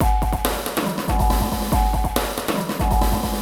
E Kit 33.wav